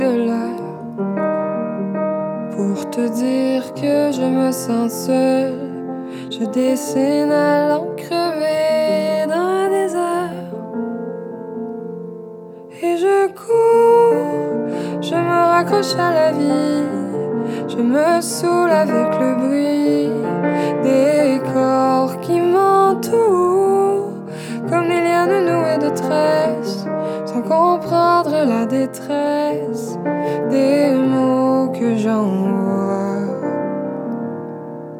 Жанр: Рок / Альтернатива / Фолк-рок